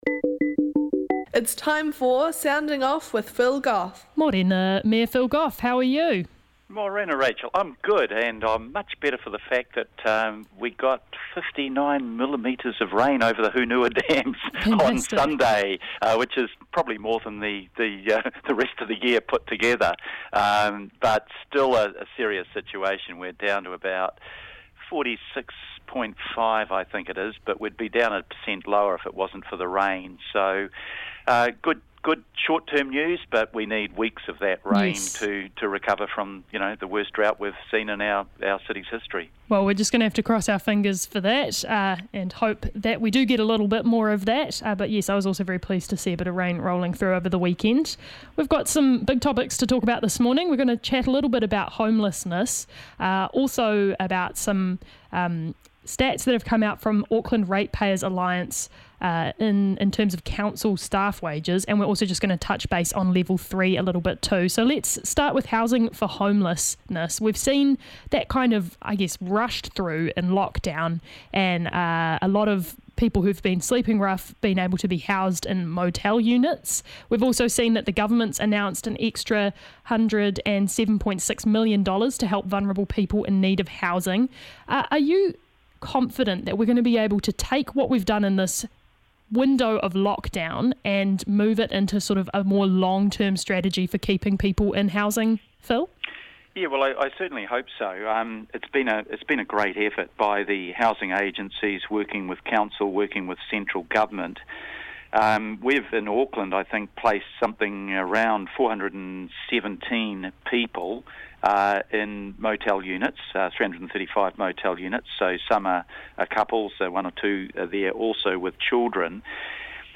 Our weekly catch up with the His Worship the Mayor of Auckland, the Honourable Phil Goff.